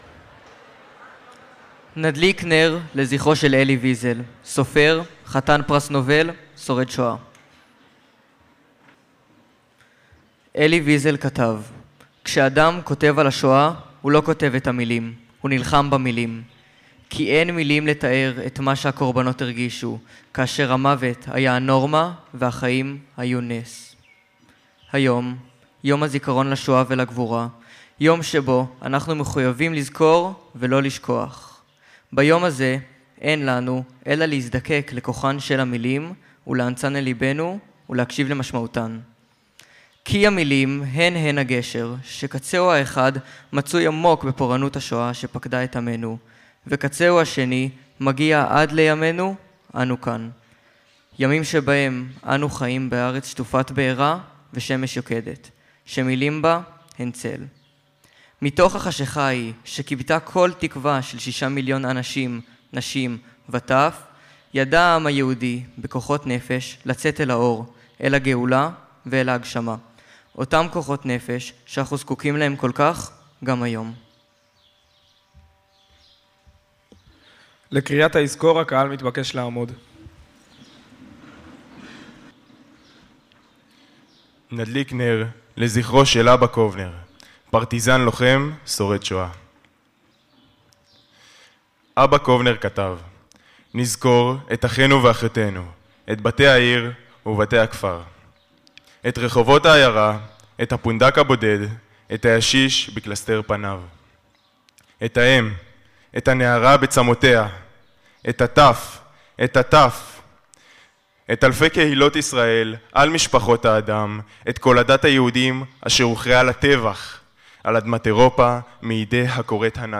טקס יום השואה 25